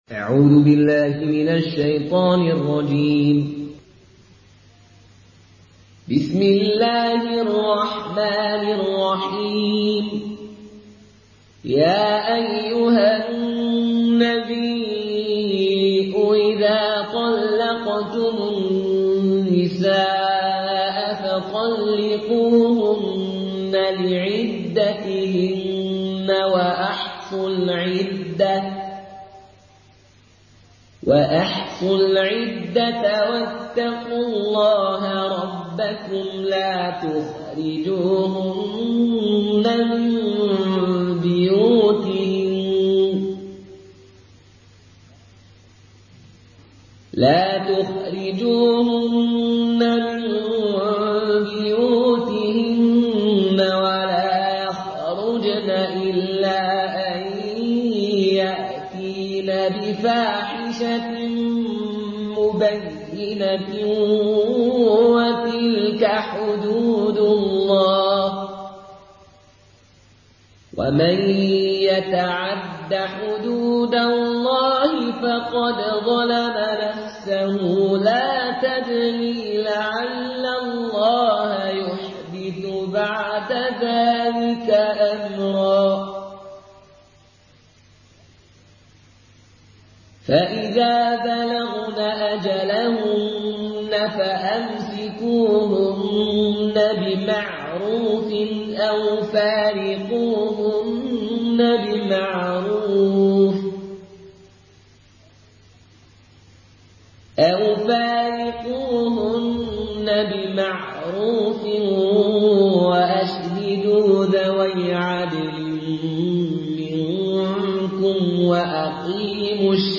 Surah Talak MP3
Murattal Qaloon An Nafi